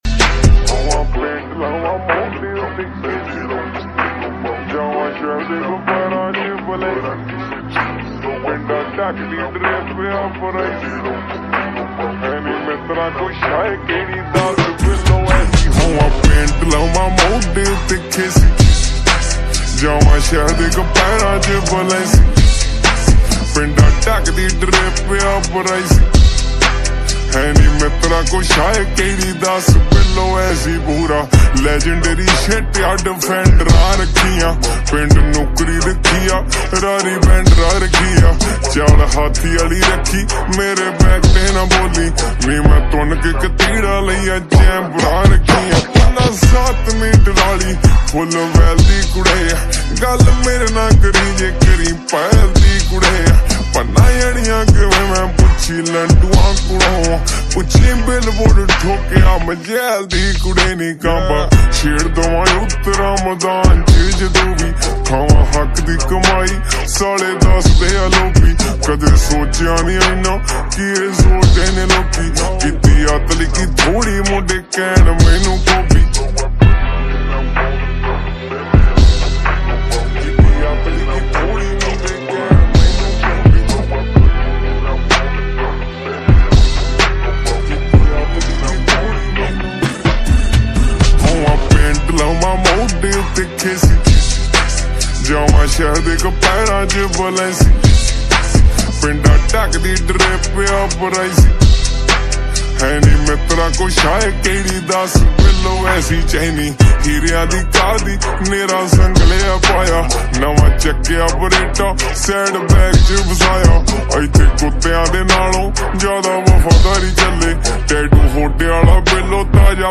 𝐒𝐎𝐋𝐕𝐄𝐃 𝐑𝐄𝐕𝐄𝐑𝐁